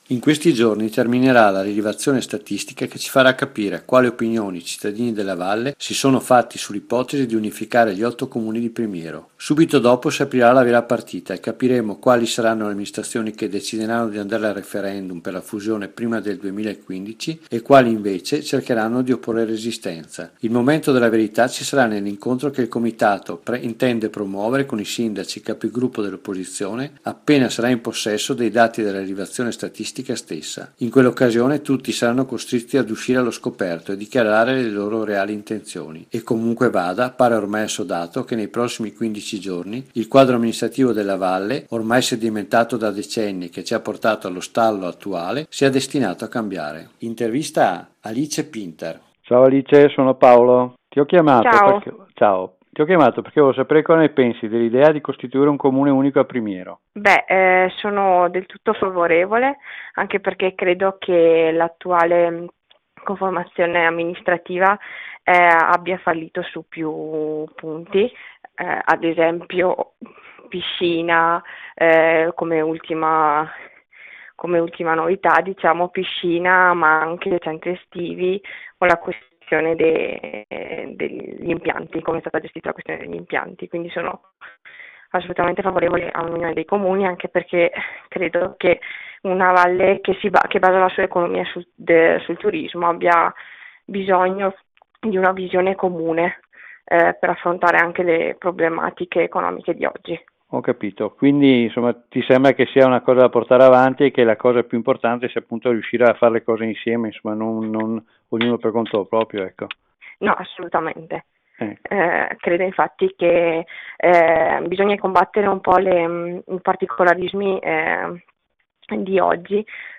Interviste per Un Primiero Meno Diviso